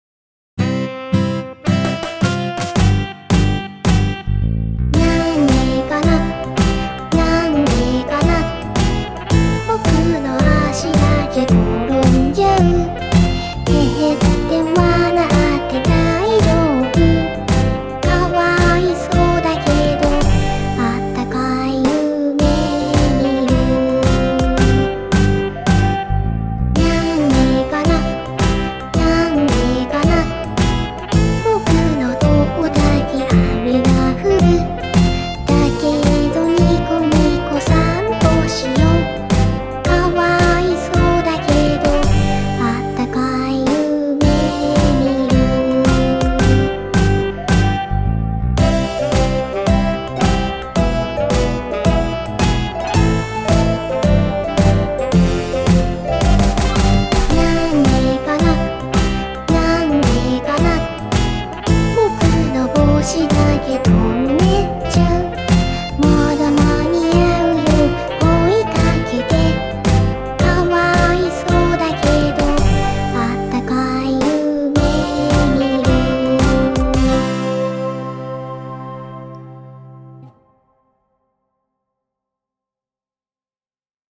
BPM: 110
可愛らしい歌が使いたい時など、ご自由にご利用ください。